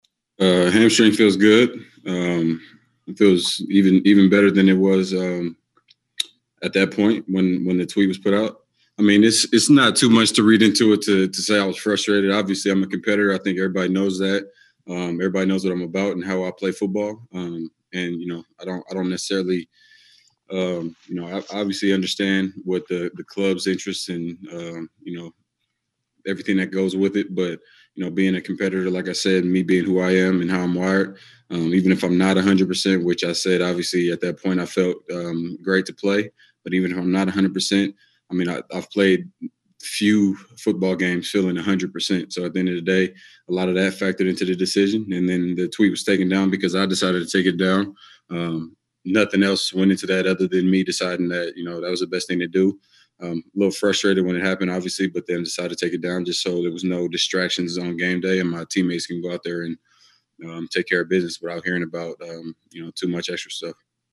After practice, he met with reporters via zoom to talk about the minor controversy from a week ago when he posted a not so subtle tweet about working through his injury and his readiness to play but indicated hours before kickoff he was being withheld by the medical staff.